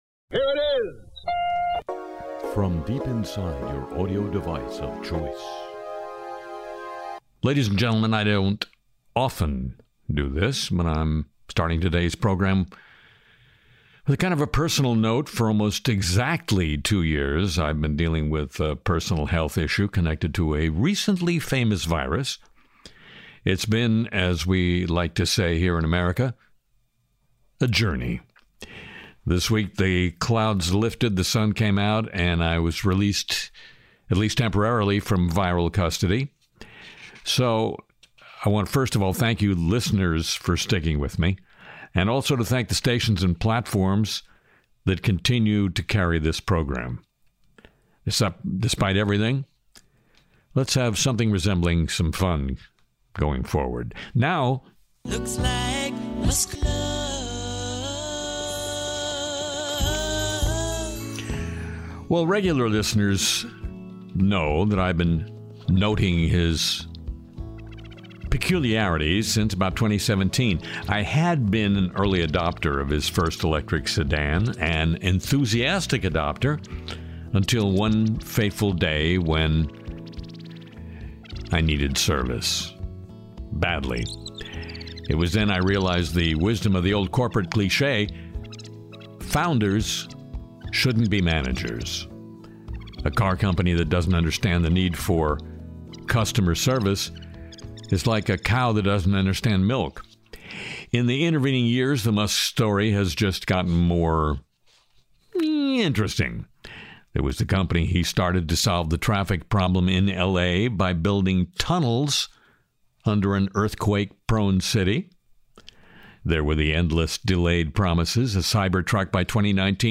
Medicare Advantage skit